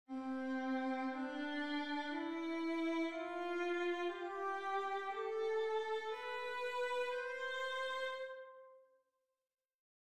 If you start on C and play all the white notes in ascending order up to the next C you get a scale you’ll recognise; it sounds like this:
b) as the mode we now call the major but which was once known as the Ionian:
Ionian